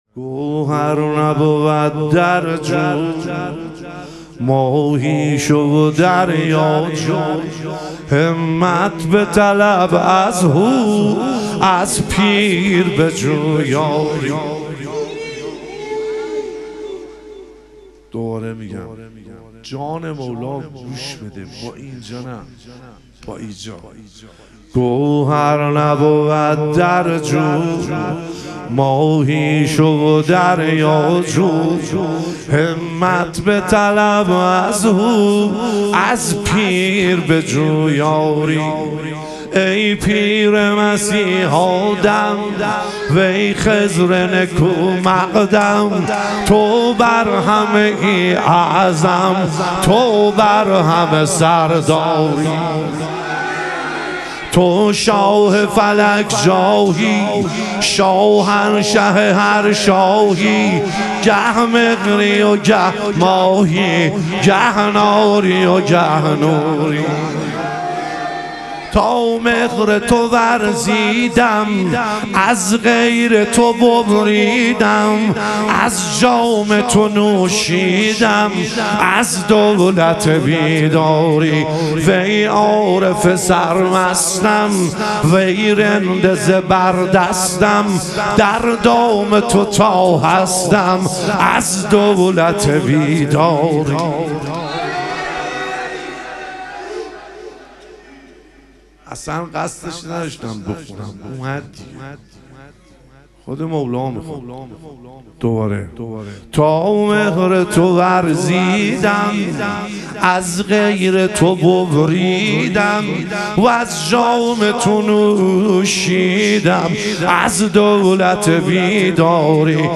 شهادت امام جواد علیه السلام - مدح و رجز